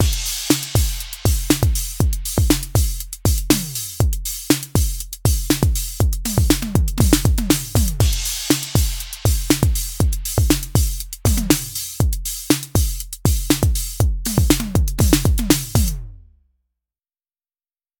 Once mixed some delay and filtering was added to add some extra space and definition.
The mixed crash sound is added to the pattern